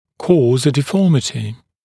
[kɔːz ə dɪ’fɔːmɪtɪ][ко:з э ди’фо:мити]вызывать деформацию, служить причиной деформации